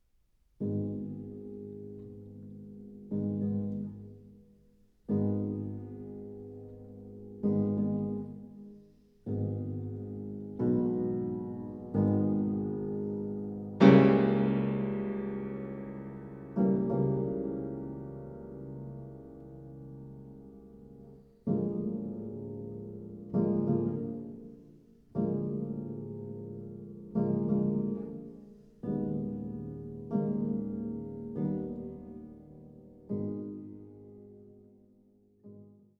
Hammerflügel